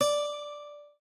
lute_d.ogg